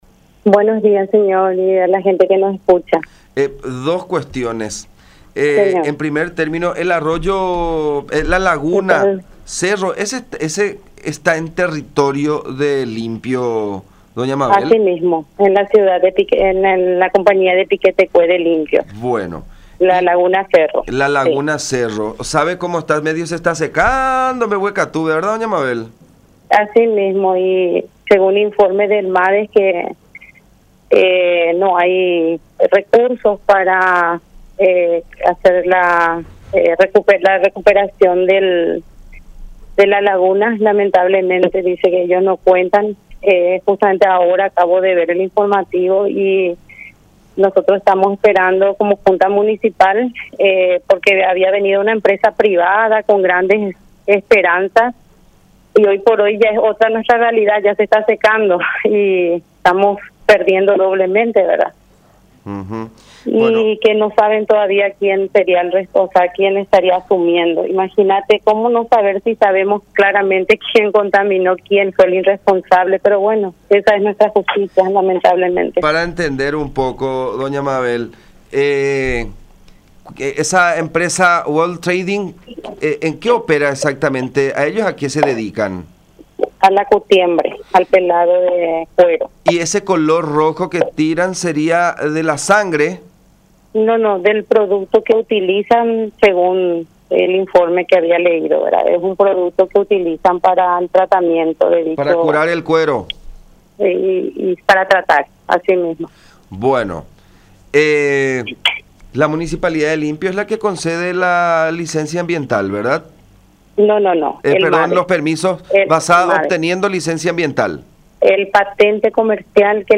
“Por una cuestión de irresponsabilidad Municipal estamos así como estamos, por la poca actitud del intendente. Lo que está haciendo es sumamente grave”, repudió Gómez en conversación con La Unión en referencia a Carlos Palacios, jefe municipal limpeño, a quien acusa de haber otorgado los documentos a la empresa Waltrading para que realice sus trabajos de curtiembre.